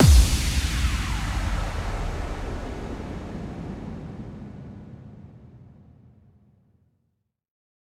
impact.ogg